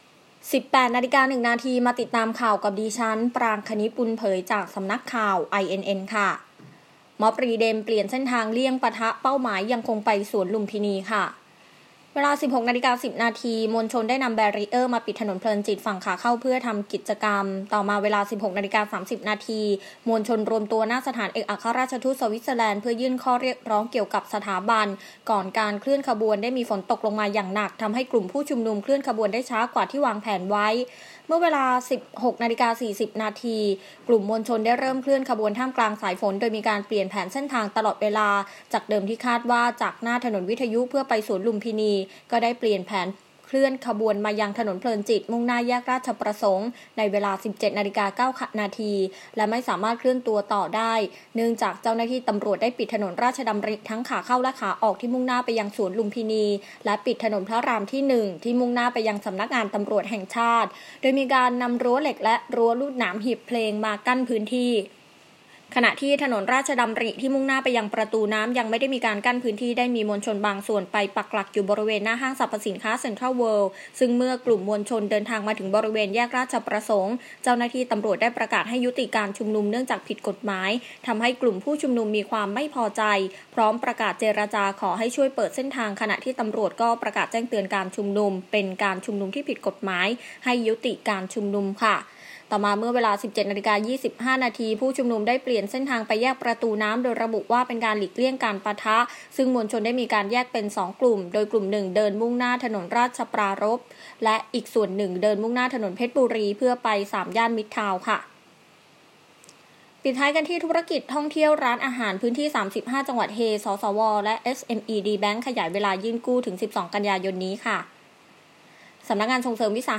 คลิปข่าวต้นชั่วโมง